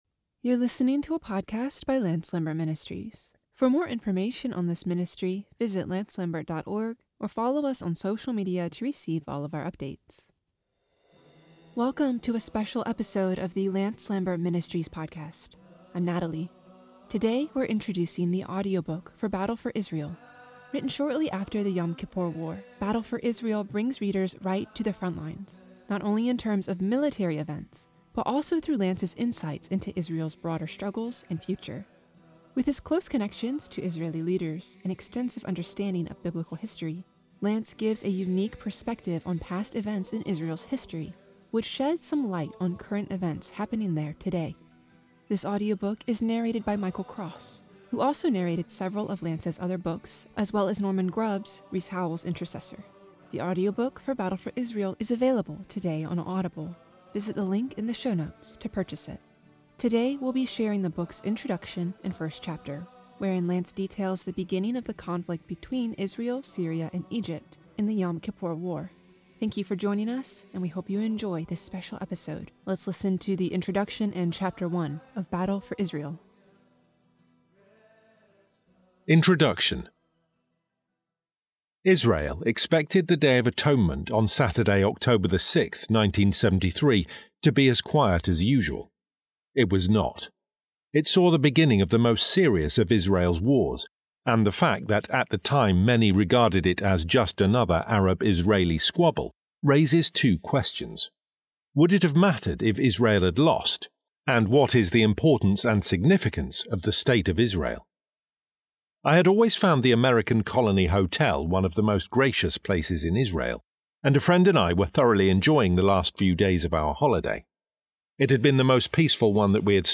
Today, we're introducing the audiobook for Battle for Israel.